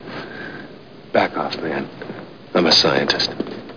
Amiga 8-bit Sampled Voice
1 channel
backoffman.mp3